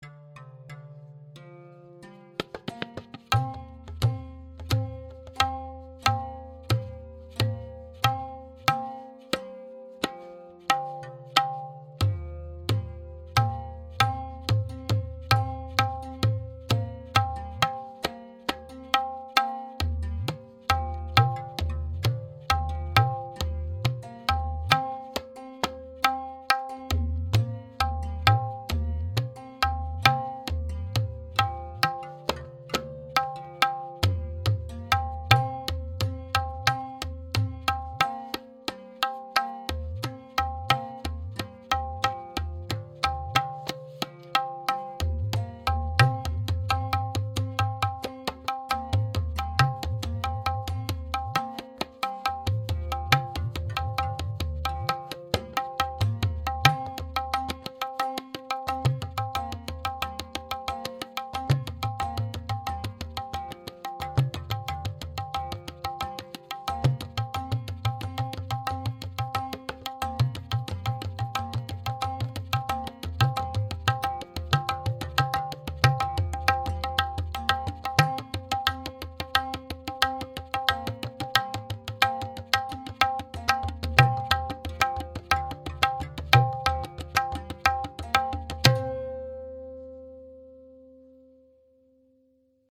Tintal Theka in Multiple Lays
Here is a performance of these 5 different lays above: ekgun (1:1), dergun (3:2), dugun (2:1), tigun (3:1), and chaugun (4:1):
Tintal_Laykari-x1-x4-Lehra.mp3